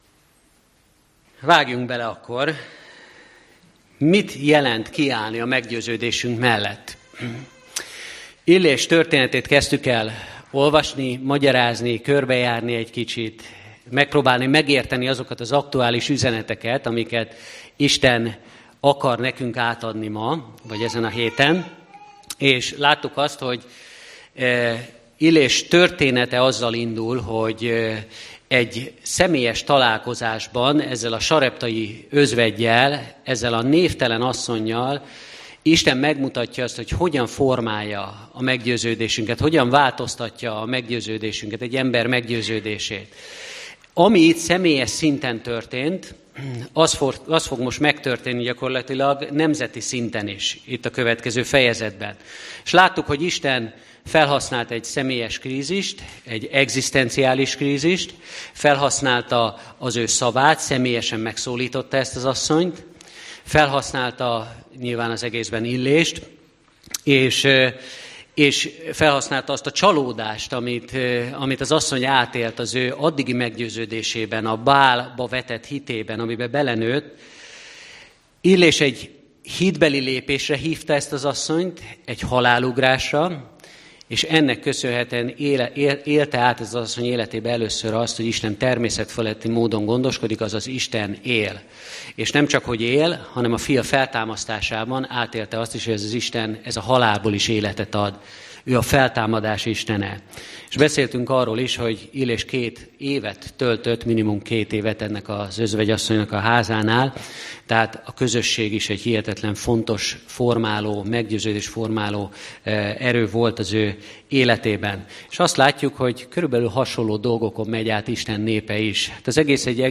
Férfiasság – Férfi szerep 2 Előadás